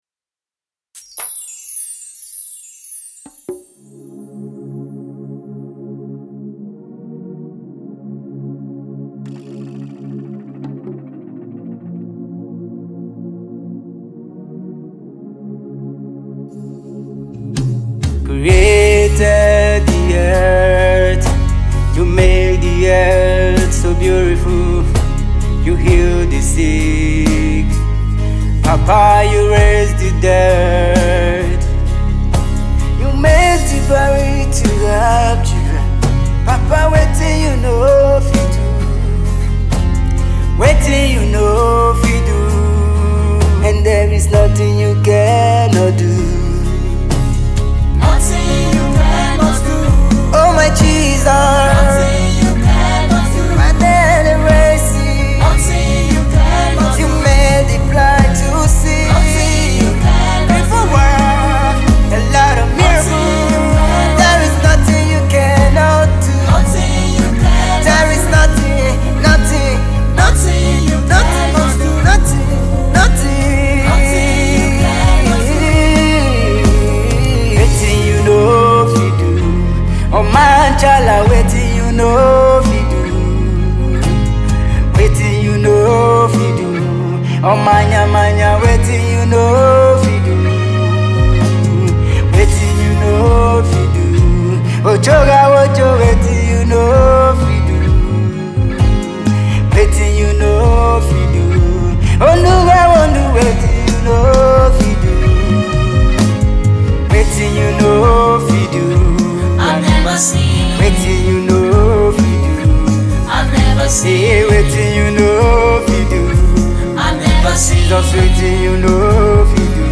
Nigerian gospel music is continuously evolving